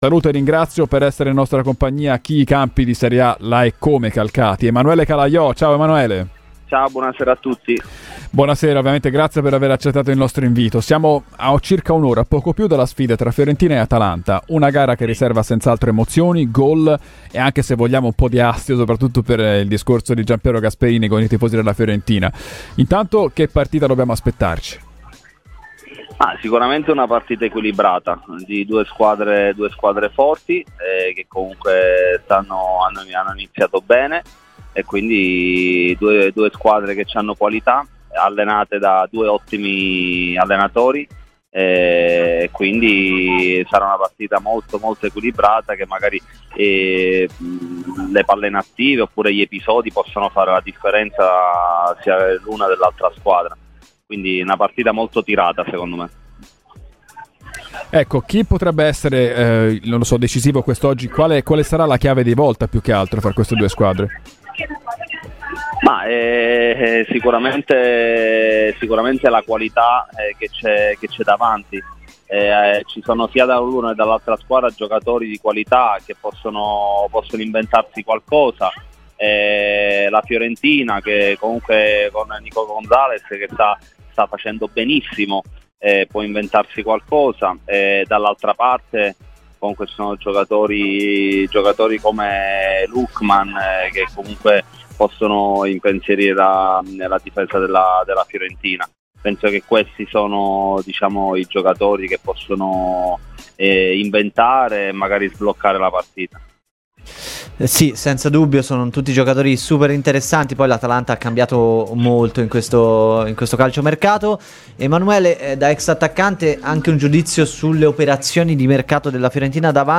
L'ex attaccante di Salernitana, Napoli, Parma e Siena, tra le altre, Emanuele Calaiò ha parlato a pochi minuti dalla partita delle 18 tra Fiorentina e Atalanta. Ecco le sue parole rilasciate a Radio Firenzeviola, durante "Viola Weekend".